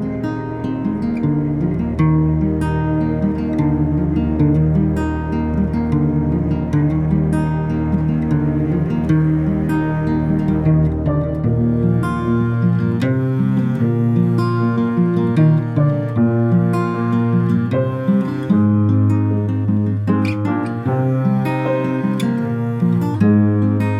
Professional Pop (2010s) Backing Tracks.